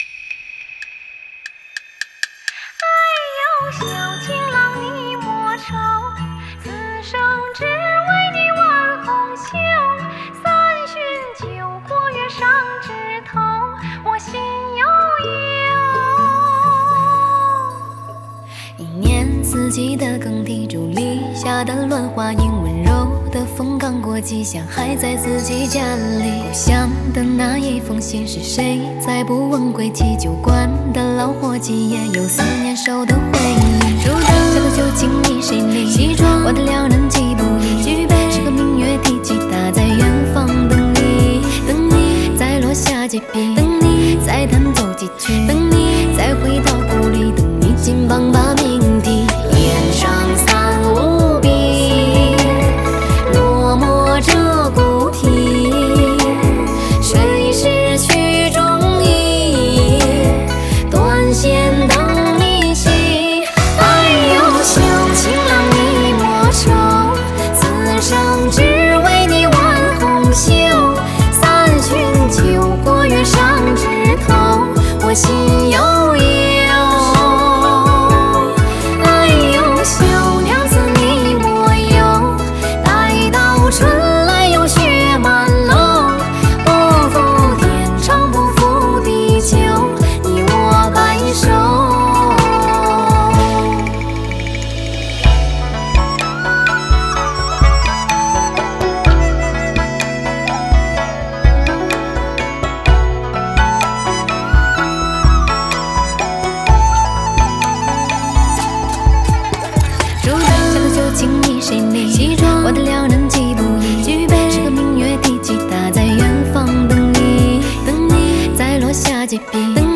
Ps：在线试听为压缩音质节选，体验无损音质请下载完整版
吉他
笛子